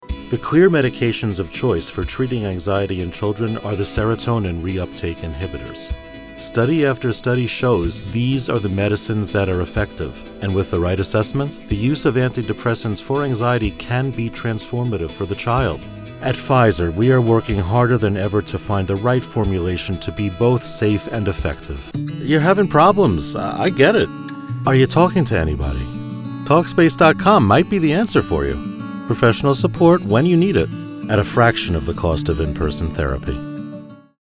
Corporate & Narration